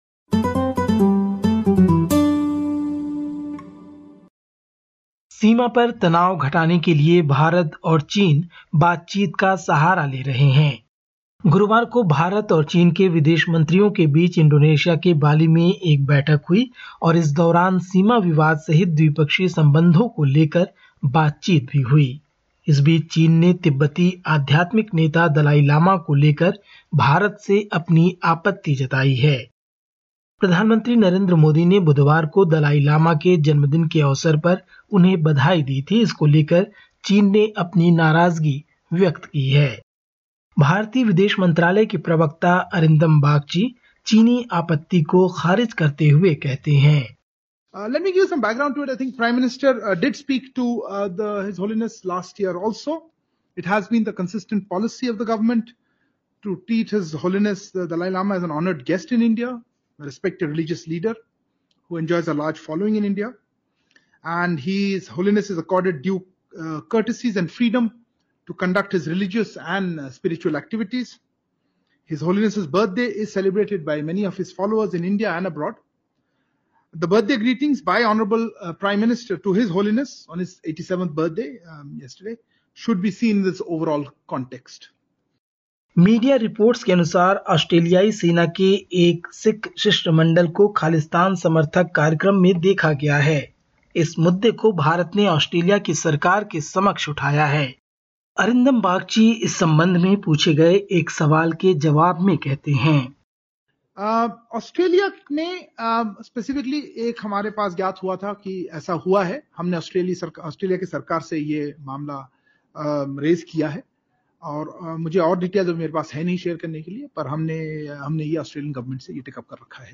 Listen to the latest SBS Hindi report from India. 08/07/2022